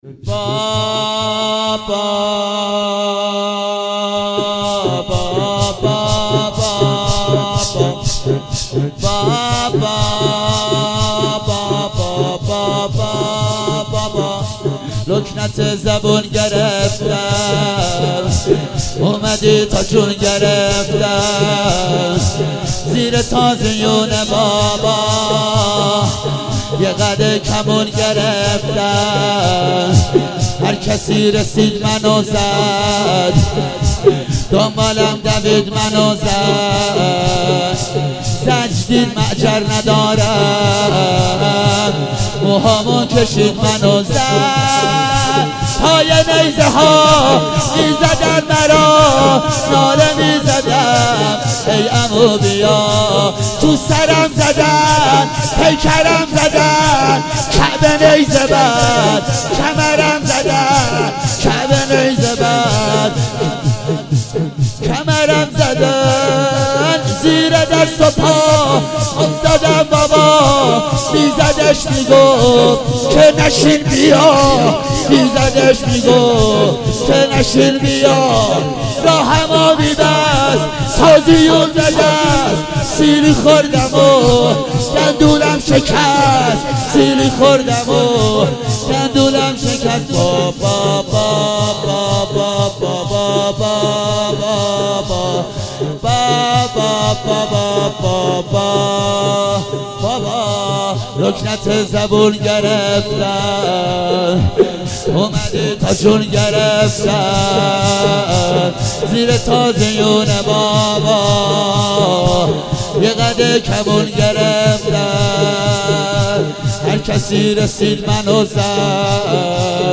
شب چهارم صفر 97 - زمینه - لکنت زبون گرفتم اومدی